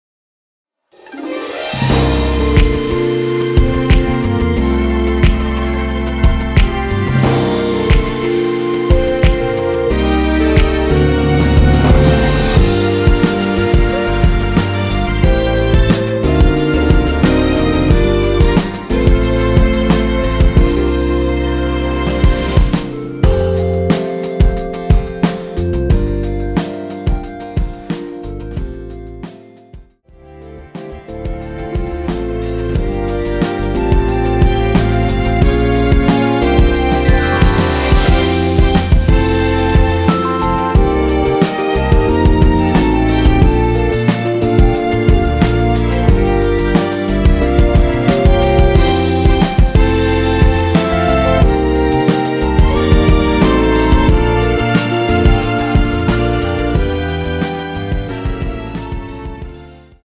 원키에서(+2)올린 (1절앞+후렴)으로 진행되는 멜로디 포함된 MR입니다.(미리듣기 확인)
노래방에서 노래를 부르실때 노래 부분에 가이드 멜로디가 따라 나와서
앞부분30초, 뒷부분30초씩 편집해서 올려 드리고 있습니다.
중간에 음이 끈어지고 다시 나오는 이유는